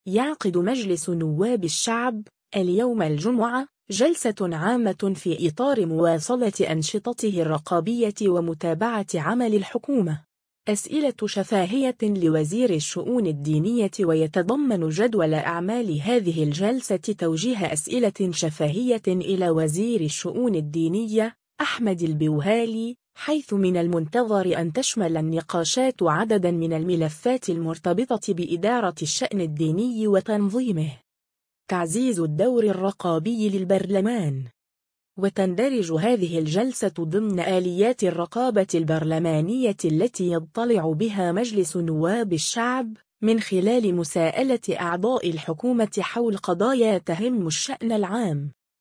البرلمان: جلسة عامة لمساءلة وزير الشؤون الدينية (فيديو)
أسئلة شفاهية لوزير الشؤون الدينية